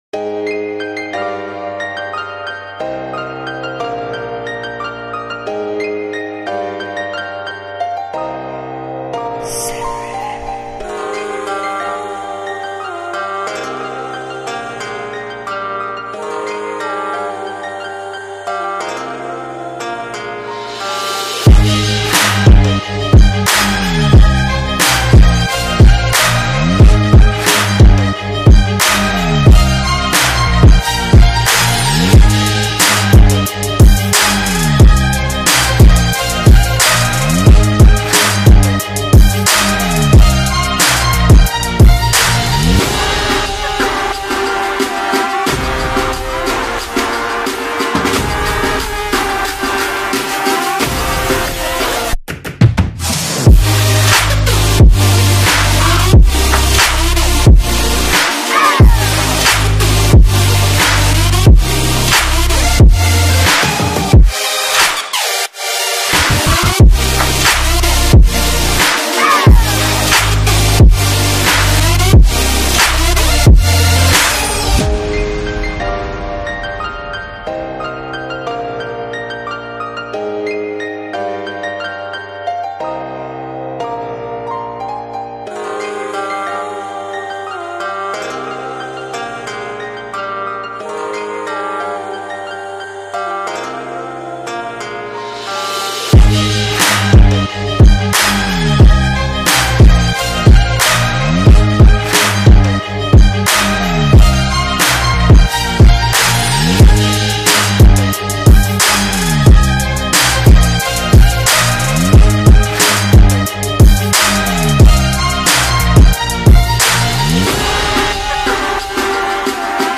Future Bass Trap Style Track